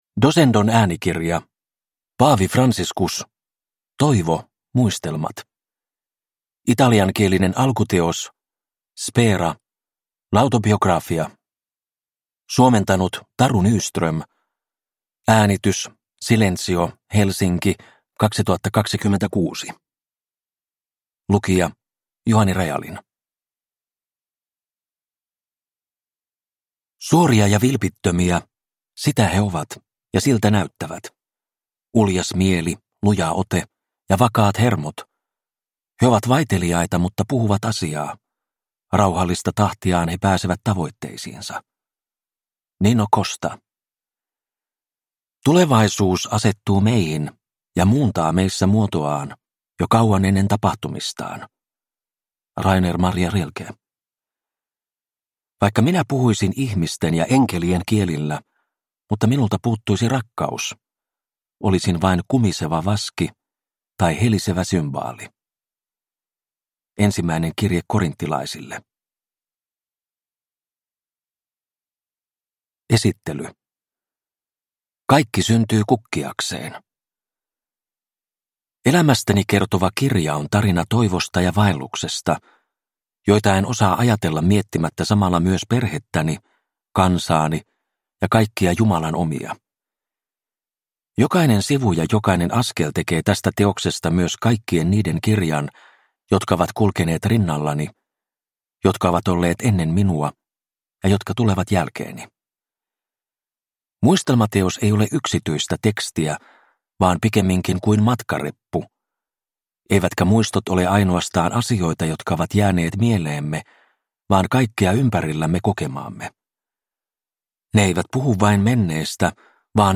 Toivo – Ljudbok